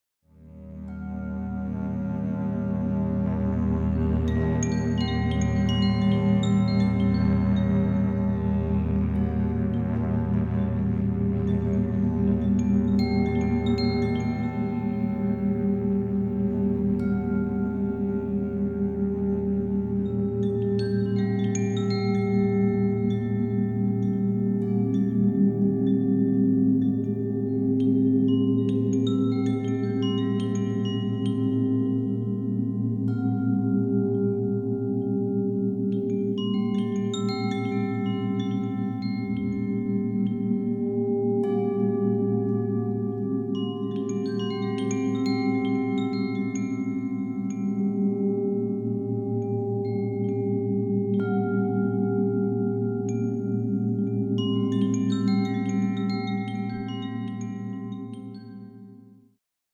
Diese CD besitzt sehr tiefe und sehr hohe Töne.